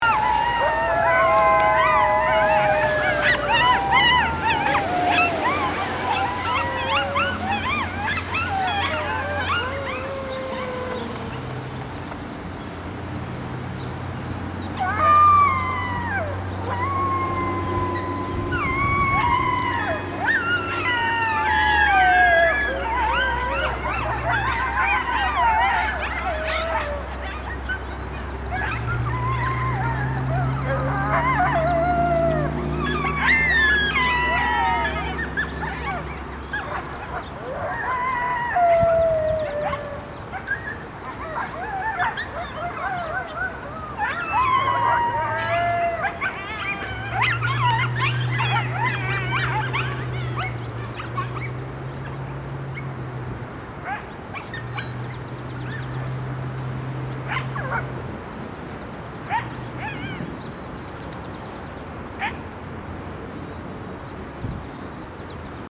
Pups Born Just A Few Months Ago Join Mom, Dad and the Fire Engine Sirens
Mom leads the chorus
Most of the sounds are coming from coyote pups hidden in various spots within the bushes! It sounds like the bushes are singing!
I don’t know how many pups are in the pack I heard singing: remember that a few coyotes, with their different pitches, can sound like many more than there are.
pups-join-the-chorus-7-21.mp3